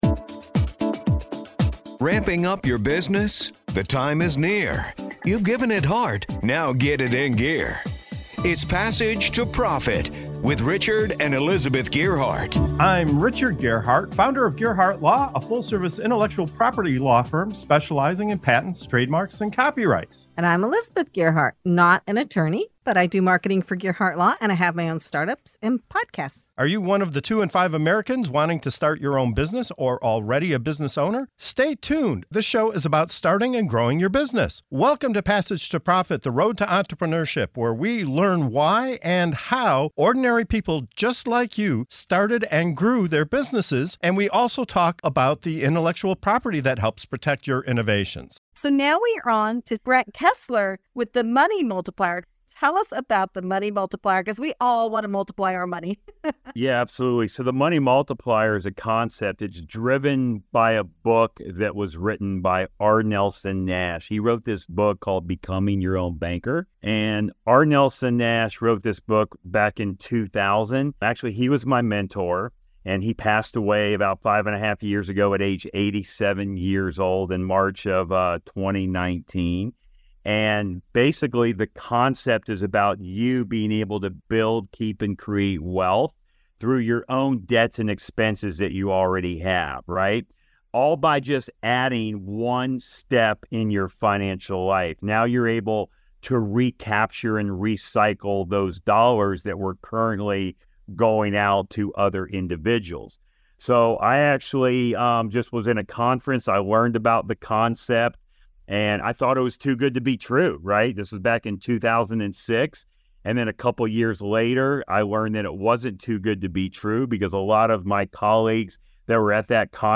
Discover how a specially designed whole life insurance policy can help you pay off debt, fund investments, and create financial freedom without working harder or taking on more risk. If you've ever wanted to multiply your money like the Rockefellers, this interview is for you!